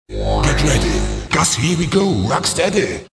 Kermis Jingle's  2013
Jingle-07-Get ready rock steady-.mp3